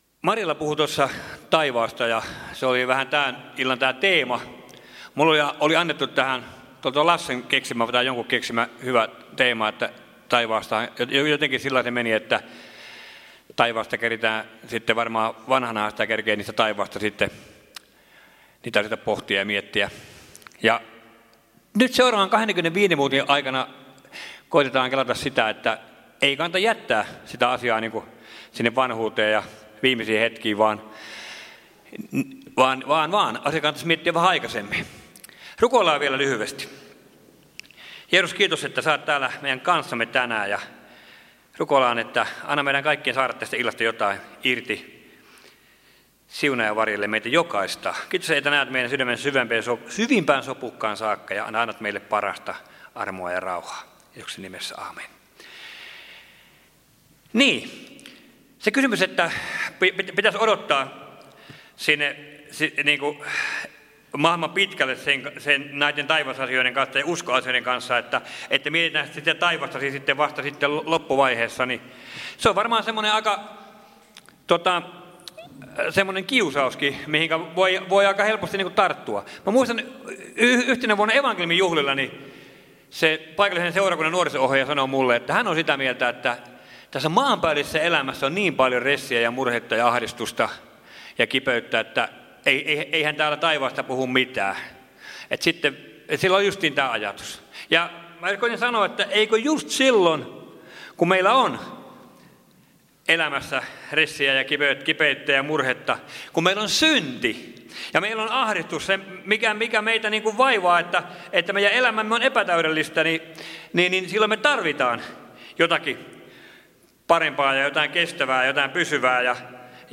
Kokoelmat: Tampereen evankeliumijuhlat 2020